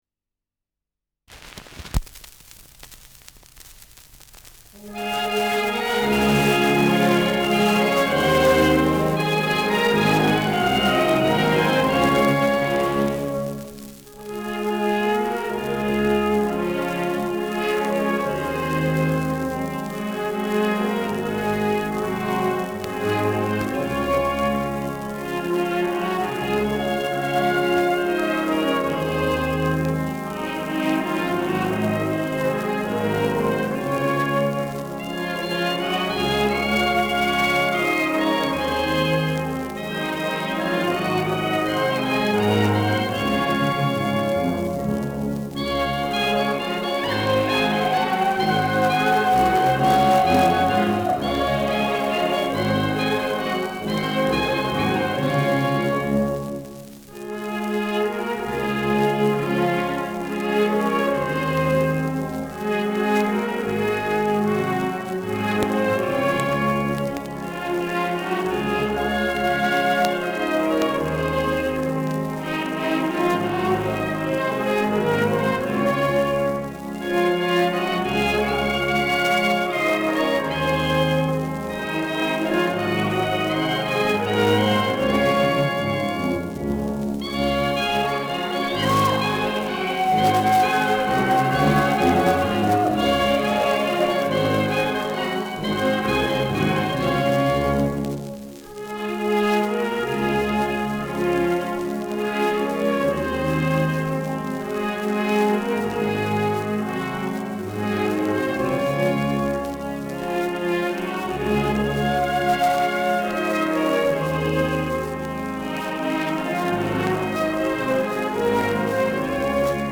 Schellackplatte
präsentes Rauschen : leichtes Knistern
Große Besetzung mit viel Hall, die einen „symphonischen Klang“ erzeugt.
[Berlin] (Aufnahmeort)